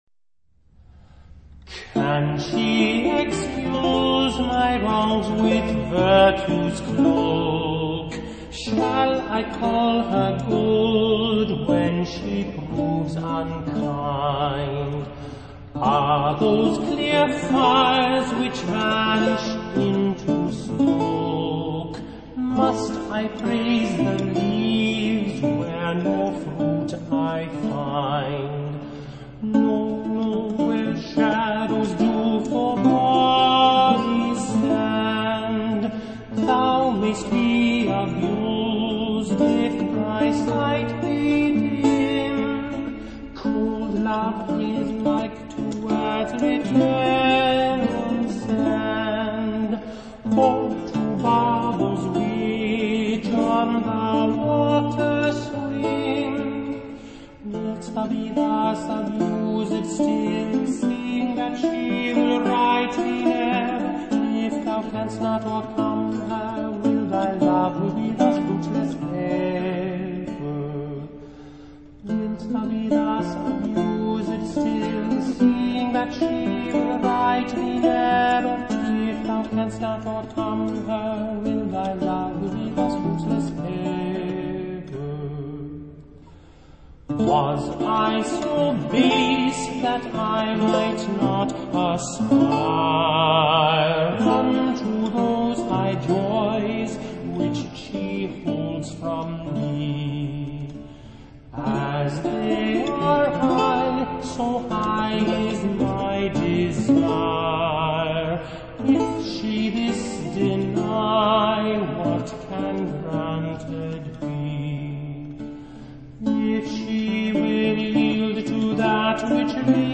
classical
chamber music